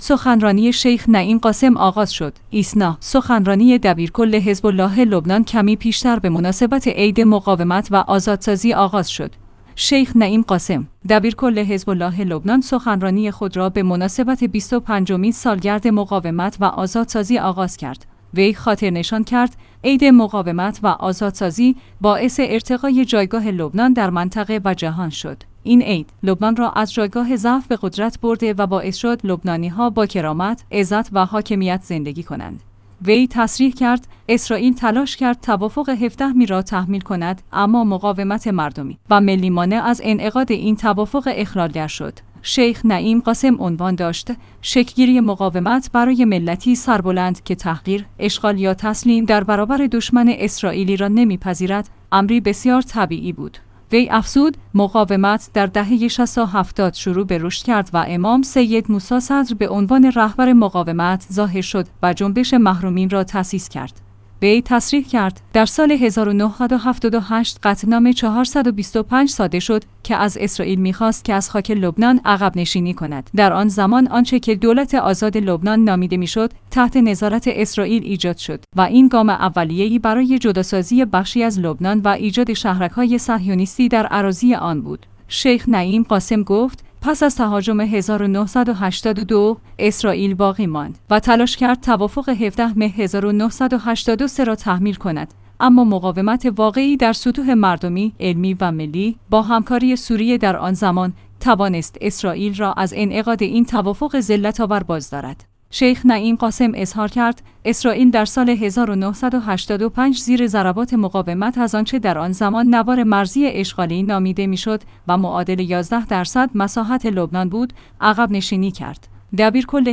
سخنرانی شیخ نعیم قاسم آغاز شد